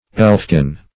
elfkin - definition of elfkin - synonyms, pronunciation, spelling from Free Dictionary Search Result for " elfkin" : The Collaborative International Dictionary of English v.0.48: Elfkin \Elf"kin\, n. A little elf.